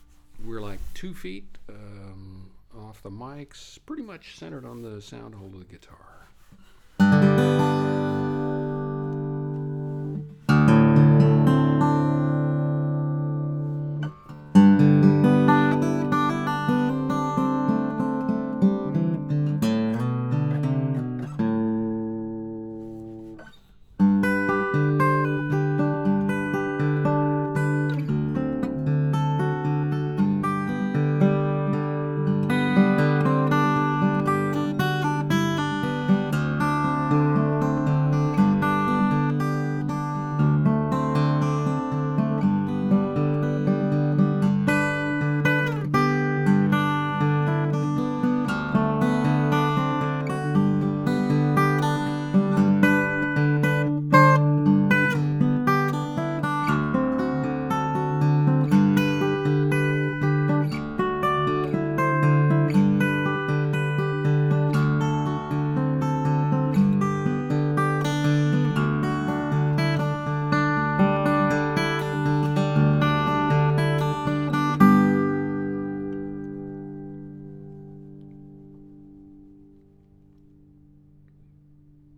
Naturally, I set the mics up as close as possible to the same position, using the same stereo arrangement.
Based on this test, it seems that a little digital EQ can make a Rode NT4 sound like a Schoeps CMC64 when recording me playing solo acoustic guitar (in this room on this day).
Try for yourself. Here are the files (these are CD spec 44.1 khz 16 bit PCM wave files, so they’re large – about 14 Mb each):
One of these is the Schoeps, one is the Rode without EQ, the other is the Rode with EQ.